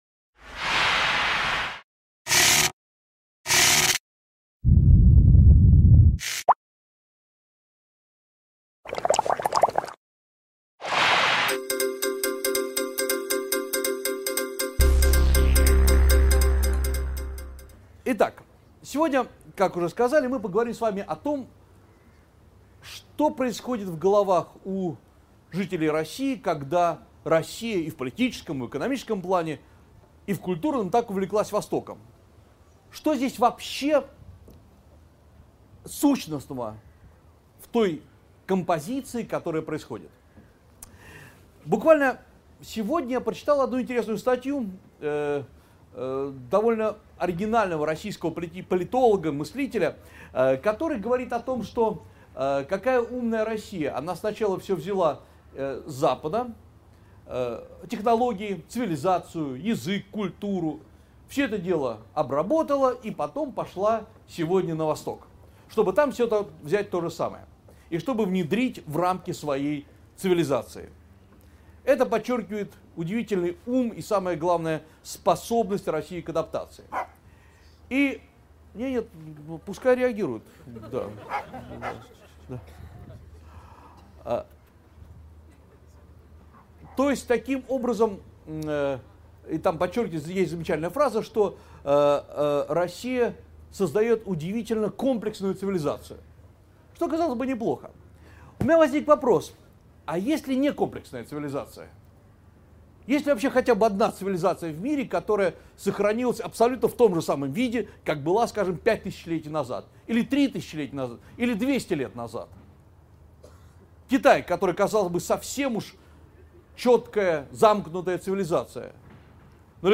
Аудиокнига Посториентализм: почему Россия так увлеклась Востоком | Библиотека аудиокниг